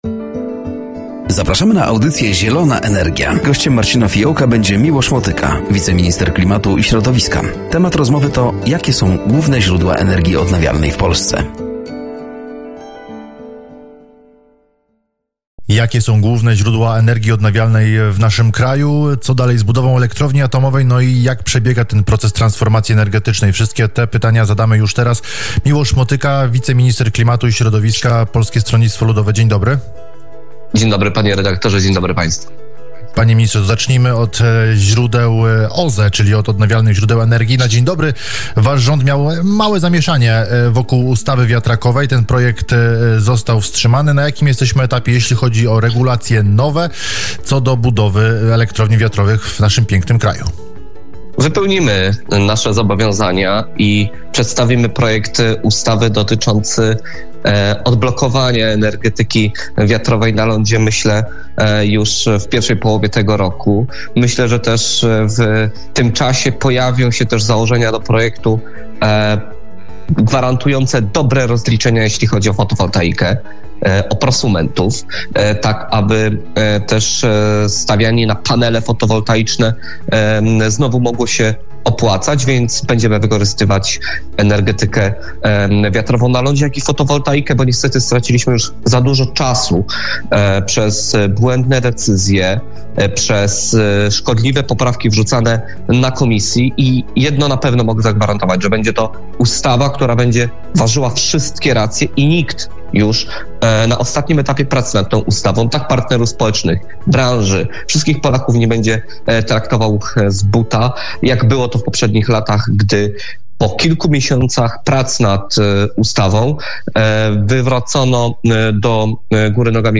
“Zielona Energia” w piątek o g. 10.15 na antenie Radia Nadzieja.